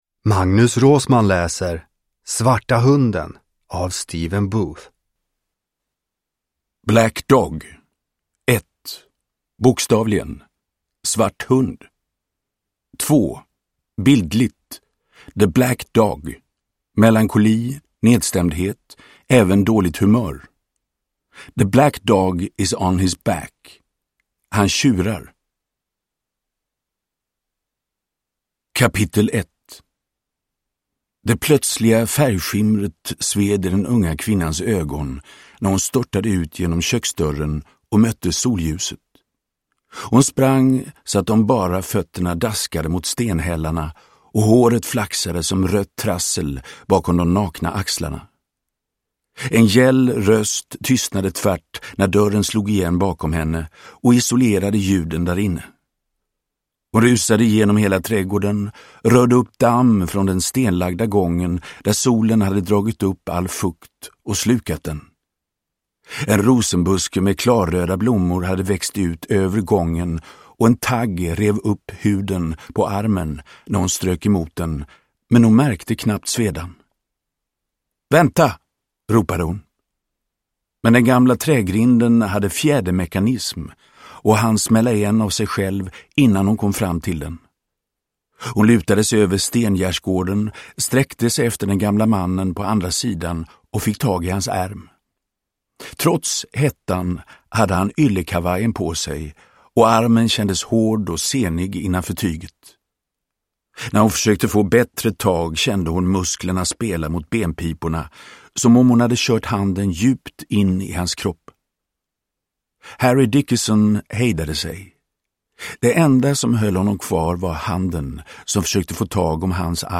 Svarta hunden – Ljudbok – Laddas ner